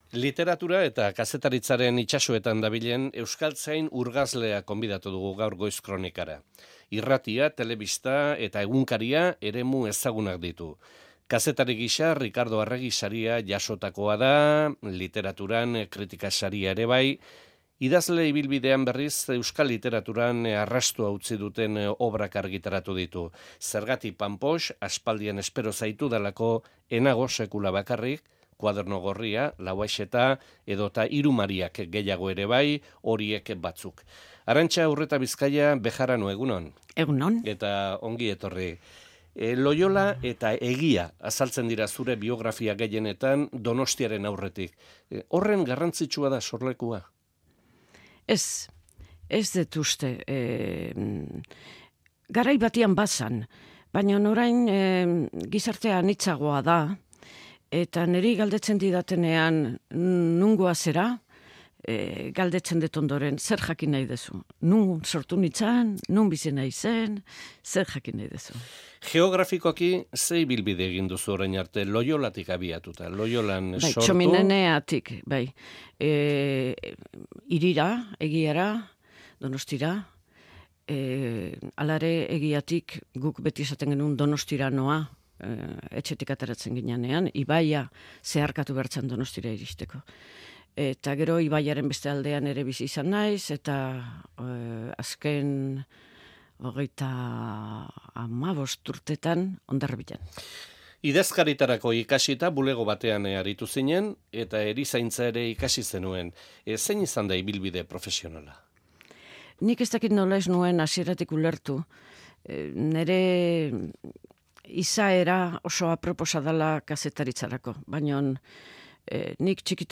Arantxa Urretabizkaia idazle eta kazetariari elkarrizketa